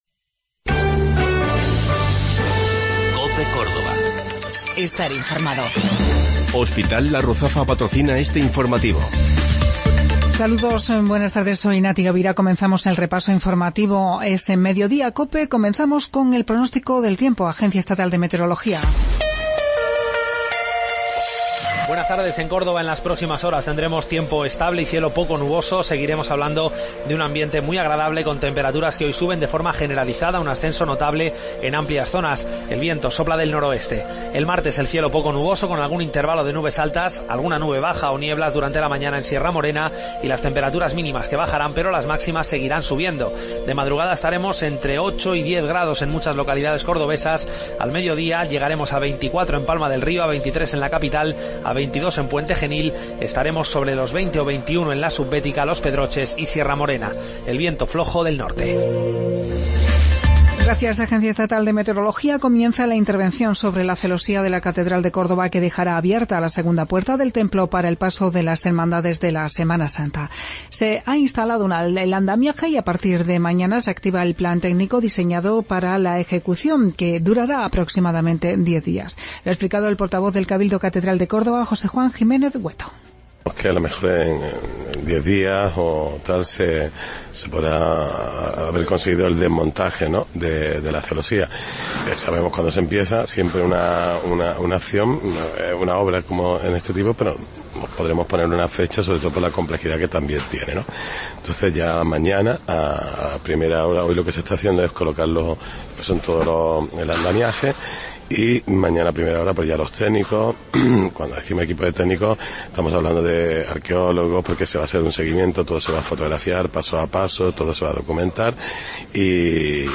Mediodía en Cope. Informativo local 6 de Marzo 2017